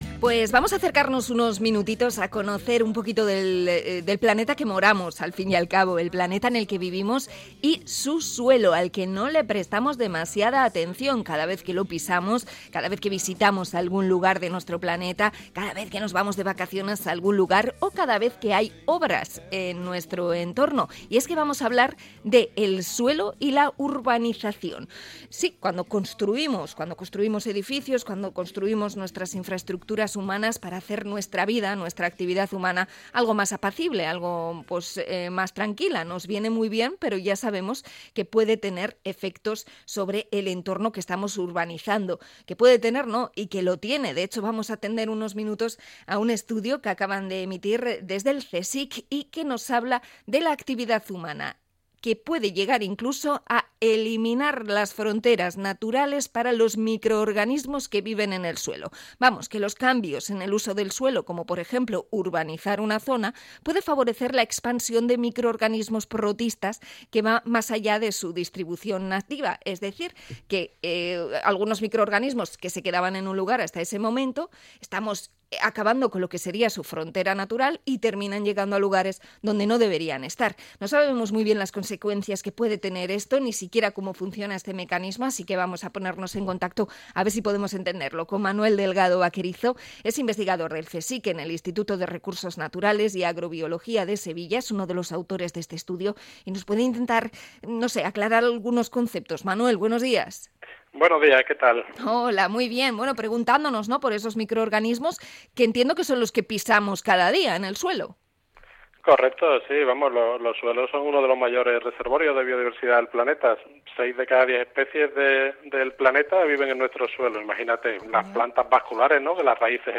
Entrevistamos al CSIC sobre los microbios de los suelos